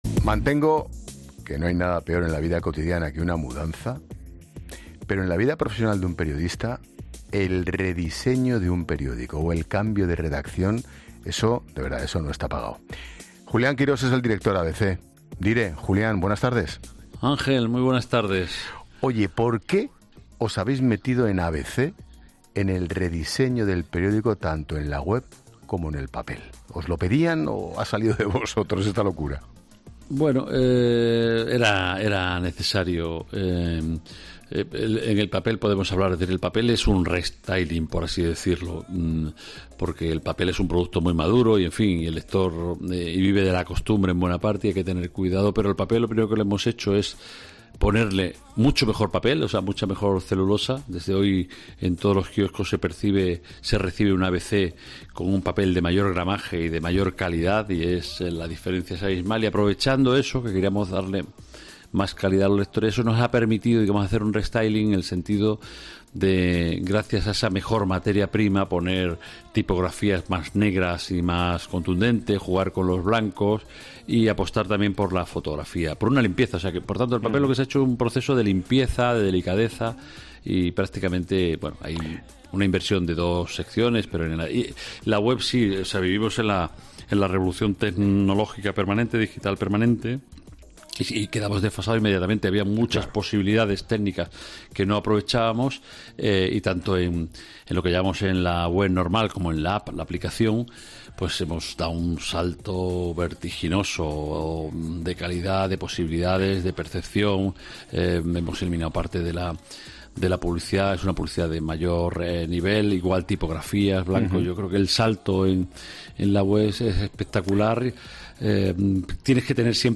Expósito entrevista